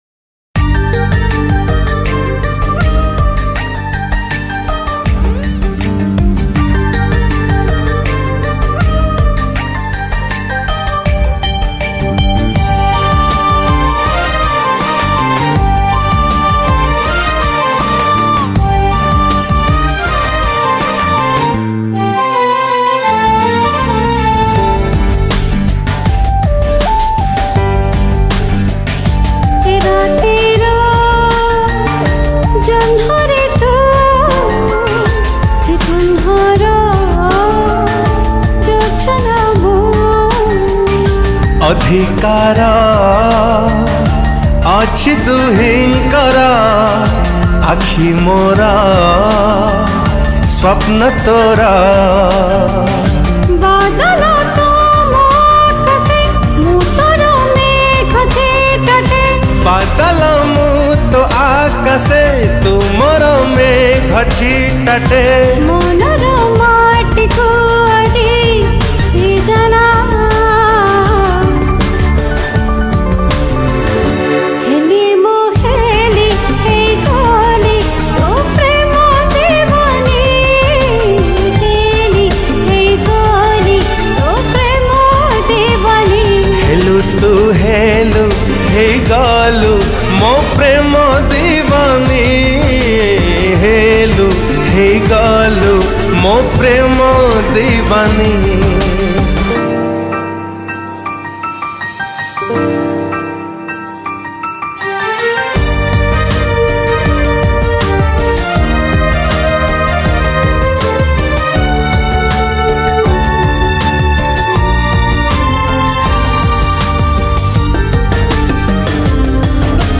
Soft Romantic Song